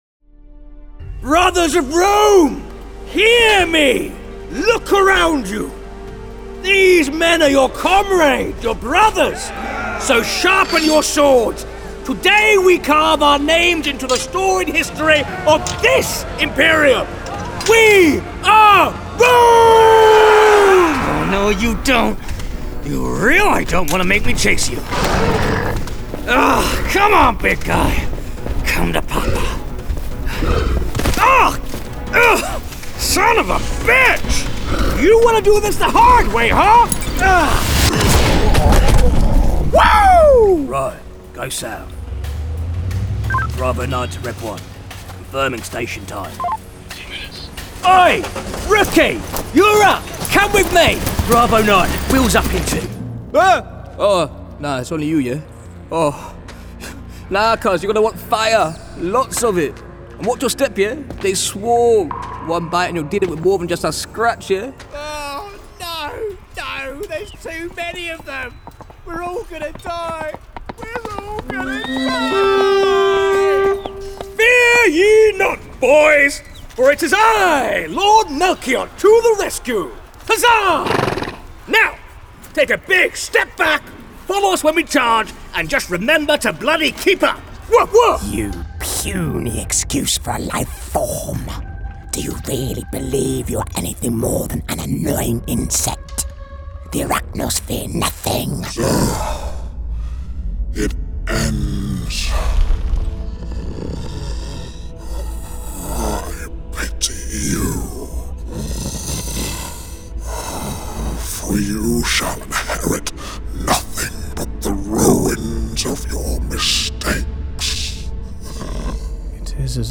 Gaming and Animation Showreel
Male
Neutral British
Gravelly
Husky
2-gaming-animation-voicereel.wav